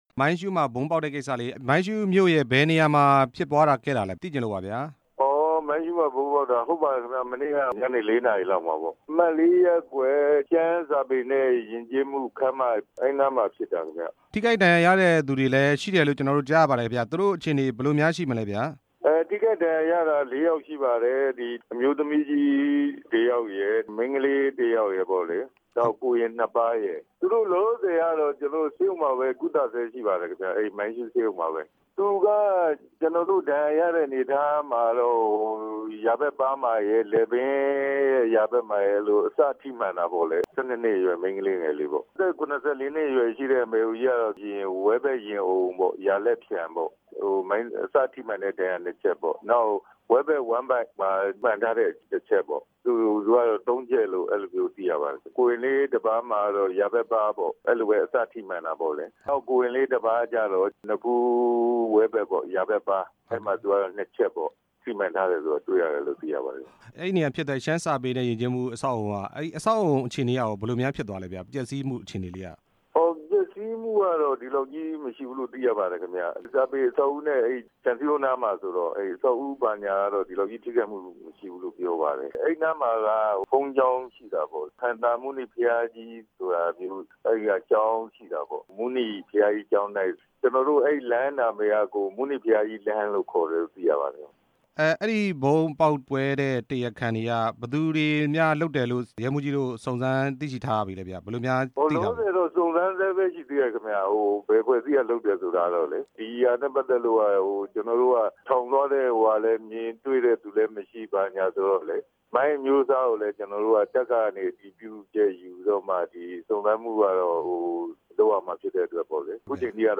မိုင်းရှူးမြို့မှာ ဗုံးကွဲ၊ ၄ ဦး ဒဏ်ရာရတဲ့ အကြောင်းမေးမြန်းချက်